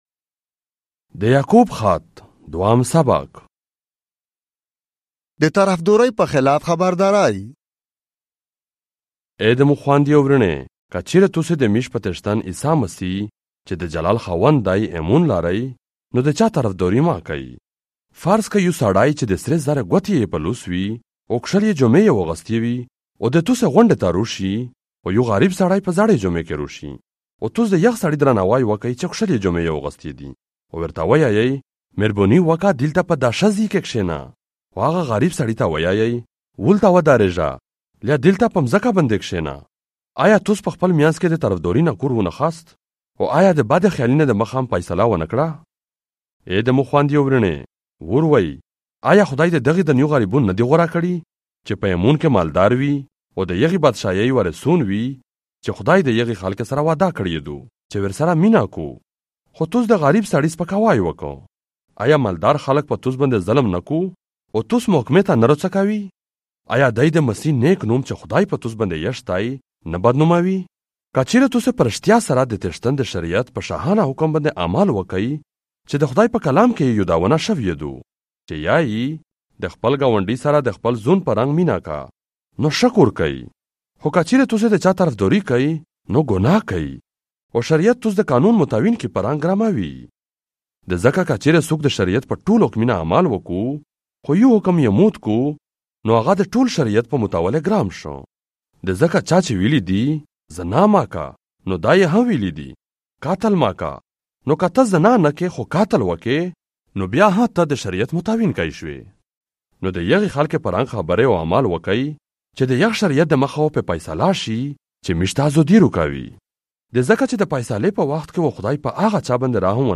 James - Chapter 2 in the Pashto language, Central - audio 2025